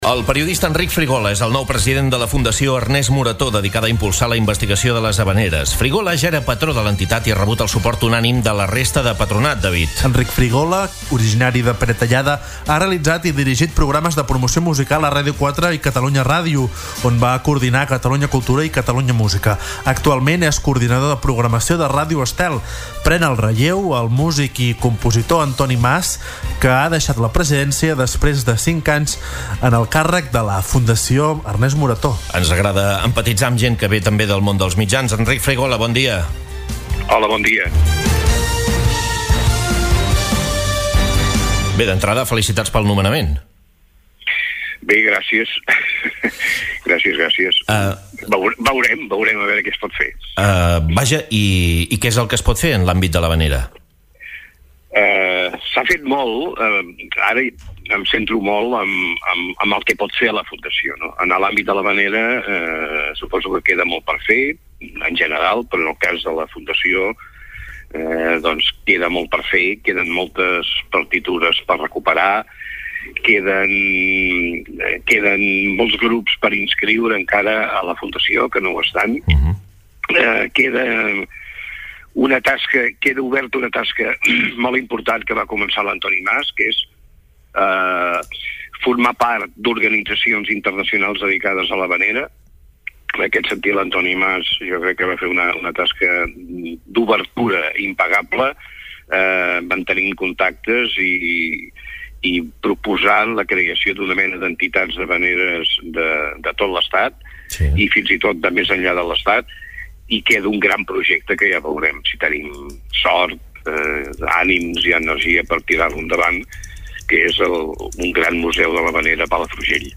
ENTREVISTA SENCERA https